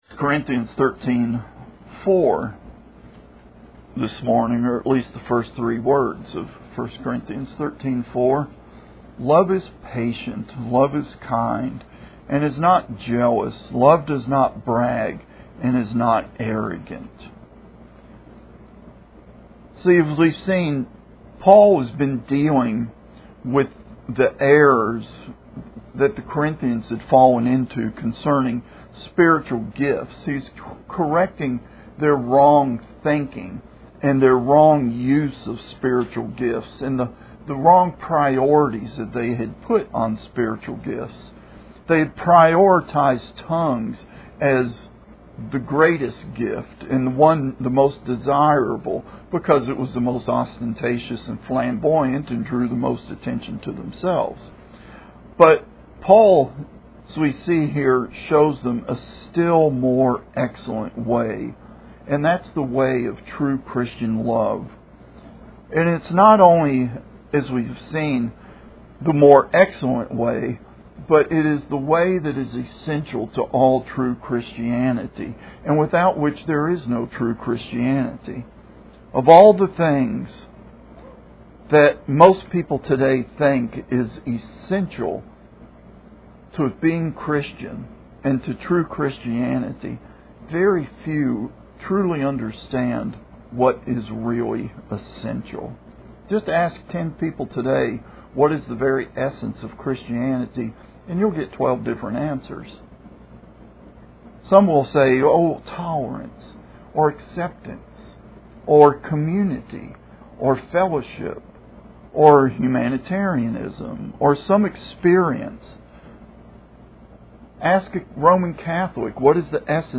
Most Recent Sermons